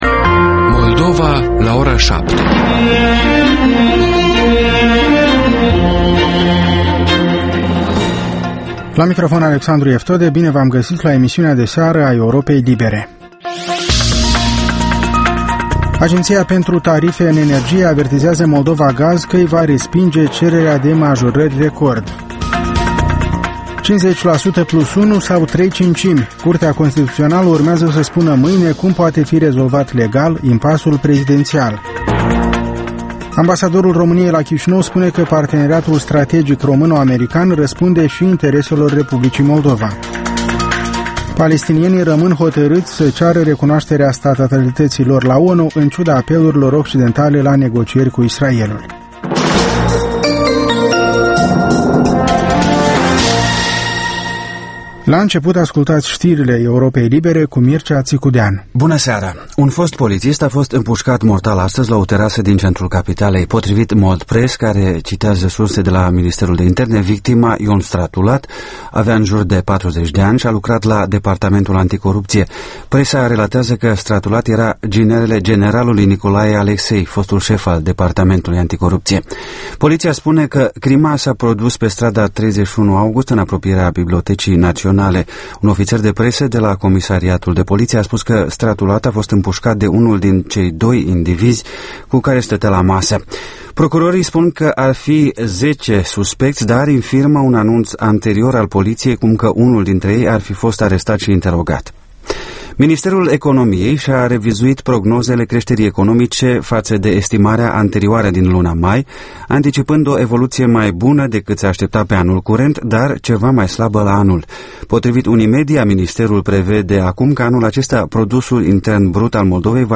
Programul de seară al Europei libere. Ştiri, interviuri, analize şi comentarii.